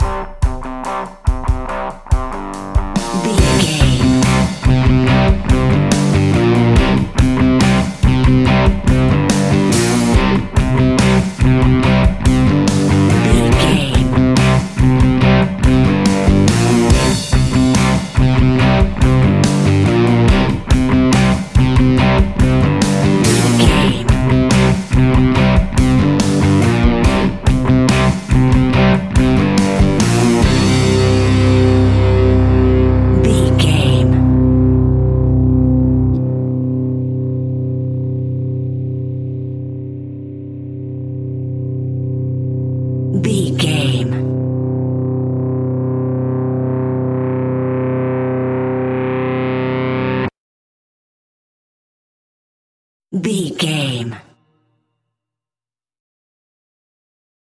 Aeolian/Minor
energetic
driving
aggressive
electric guitar
bass guitar
drums
hard rock
distortion
rock instrumentals
distorted guitars
hammond organ